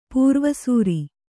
♪ pūrva sūri